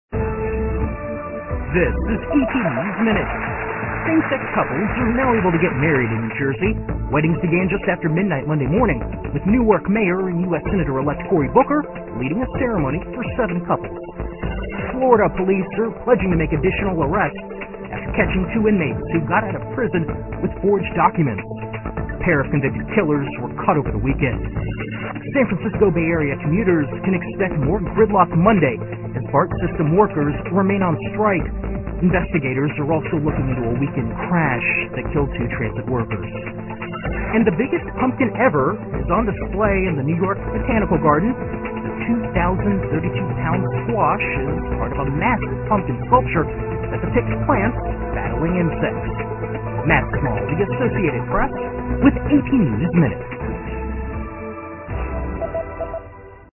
在线英语听力室美联社新闻一分钟 AP 2013-10-24的听力文件下载,美联社新闻一分钟2013,英语听力,英语新闻,英语MP3 由美联社编辑的一分钟国际电视新闻，报道每天发生的重大国际事件。电视新闻片长一分钟，一般包括五个小段，简明扼要，语言规范，便于大家快速了解世界大事。